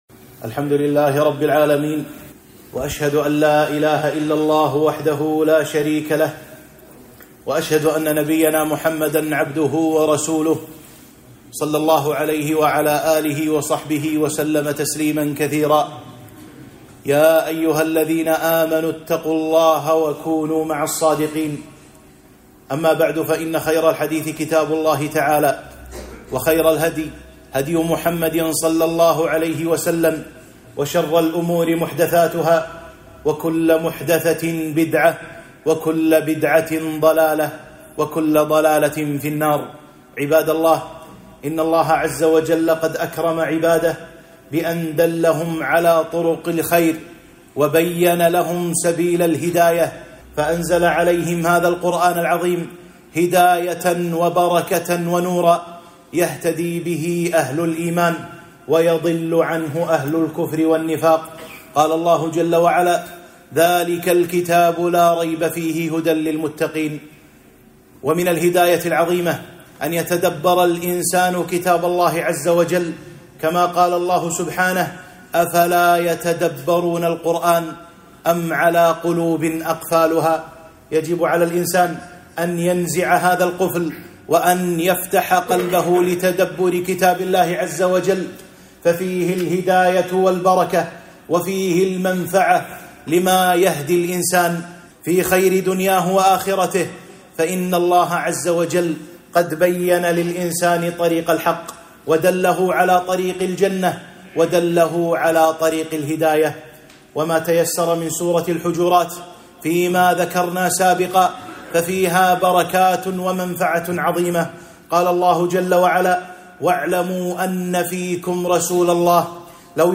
2- خطبة - فوائد ووقفات مع سورة الحجرات - الوقفة الثانية